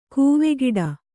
♪ kūve giḍa